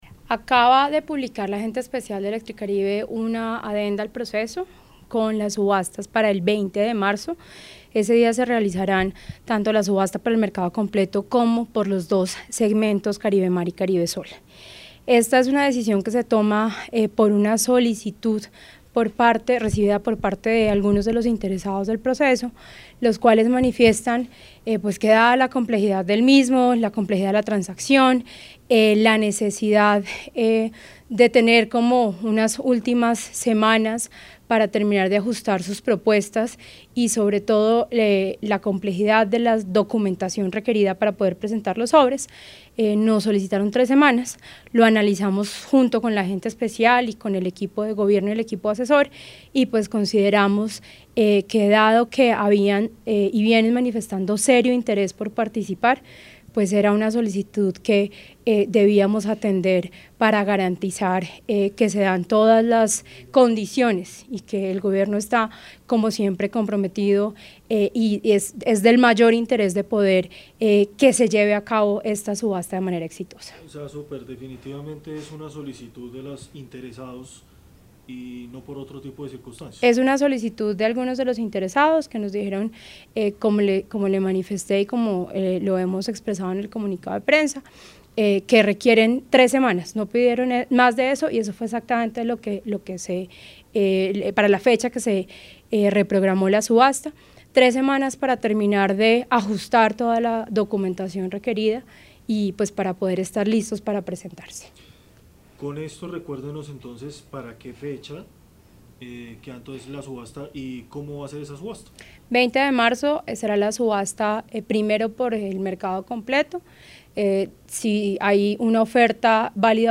Declaraciones Superintendente Natasha Avendaño García